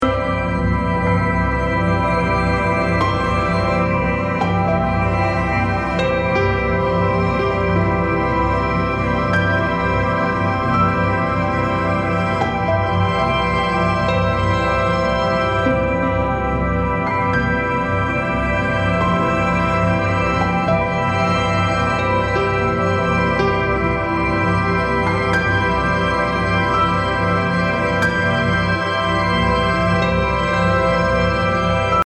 Royalty Free Music（無料音楽）
BPM 60